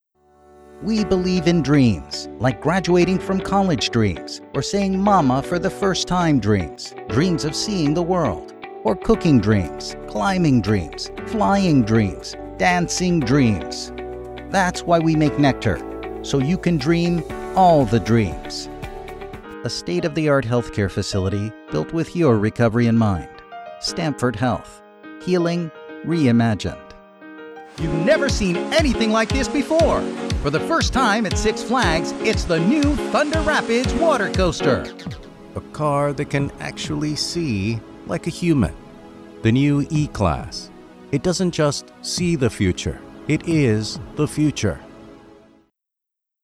American English Latin American Spanish voice over talent offering affordable voice overs and translation services at your service!
🎧 Listen, watch, and download my voice over demos right here—and when you’re ready, let’s talk!